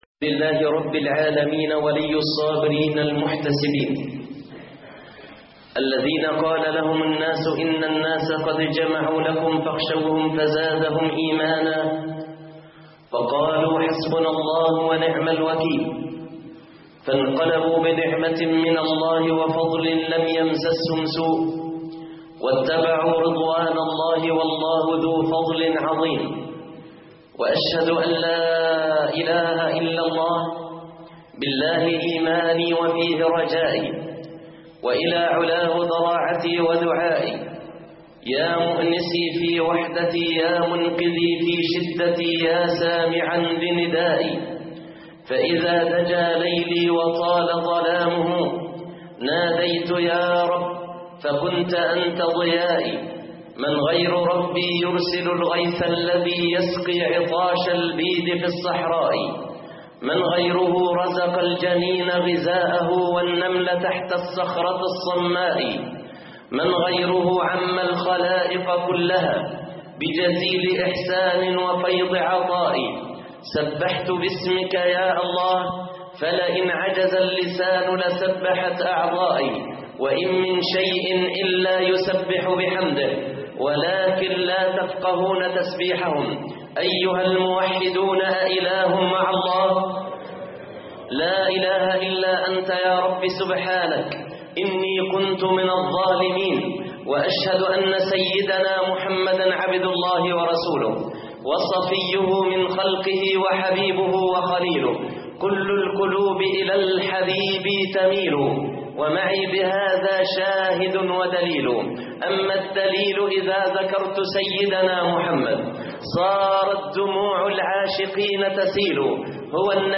التقوى جنود الله - خطب الجمعه